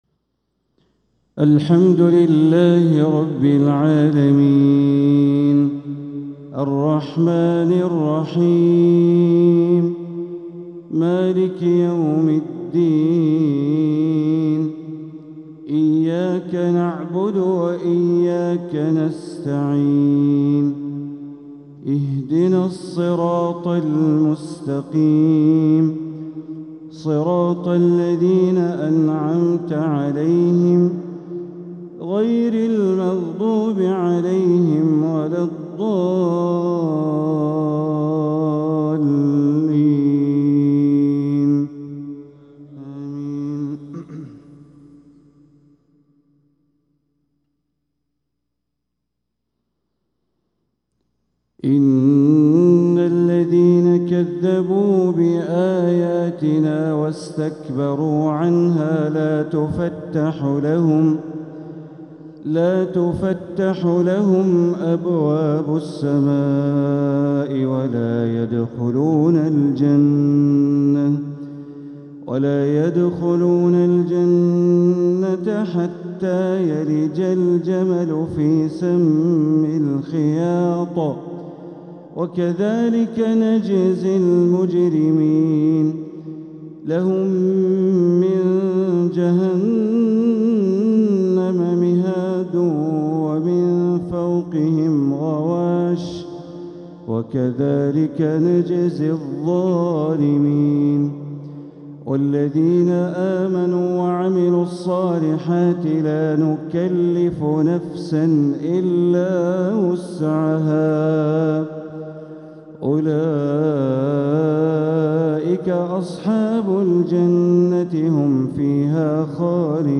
تلاوة من سورة الأعراف ٤٠-٥٣| فجر الثلاثاء ١٥ ربيع الآخر ١٤٤٧ > 1447هـ > الفروض - تلاوات بندر بليلة